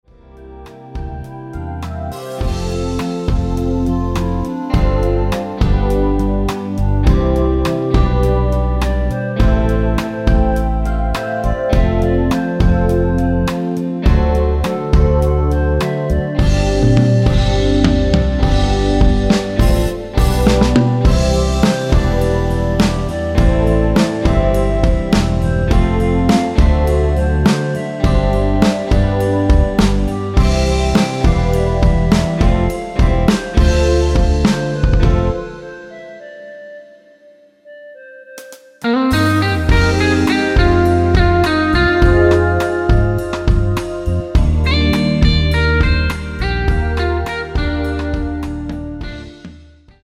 원키에서(-1)내린 멜로디 포함된 MR입니다.(미리듣기 확인)
◈ 곡명 옆 (-1)은 반음 내림, (+1)은 반음 올림 입니다.
노래방에서 노래를 부르실때 노래 부분에 가이드 멜로디가 따라 나와서
앞부분30초, 뒷부분30초씩 편집해서 올려 드리고 있습니다.
중간에 음이 끈어지고 다시 나오는 이유는